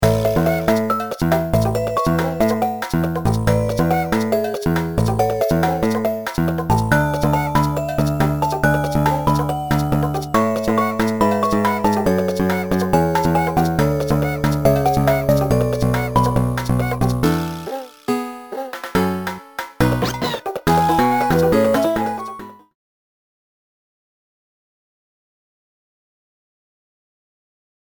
Trimmed, added fadeout
This is a sample from a copyrighted musical recording.